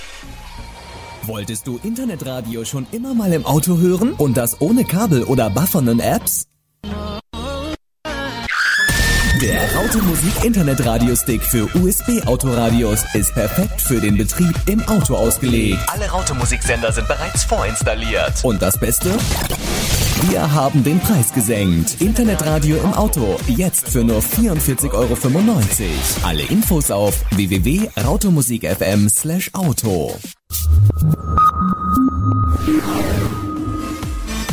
das erste ist dieser bulgarische Chor